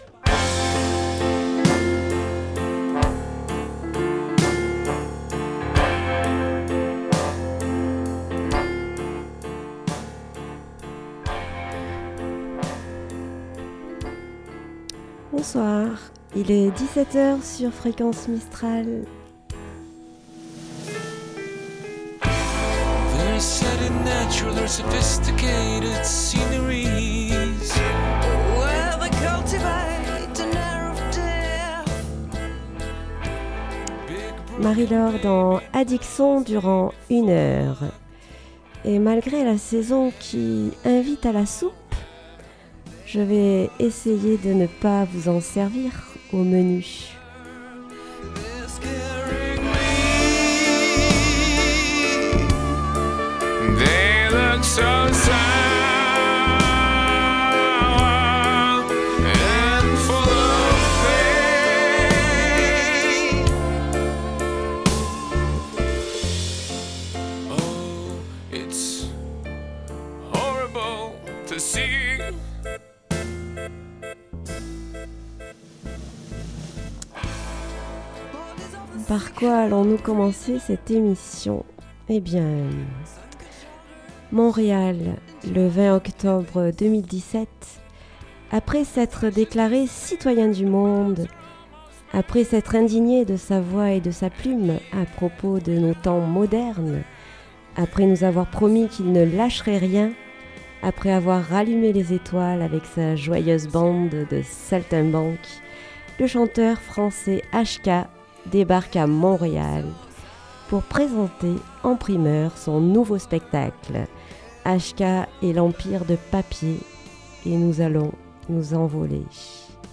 Addic Son - Emission musicale du 13 décembre 2018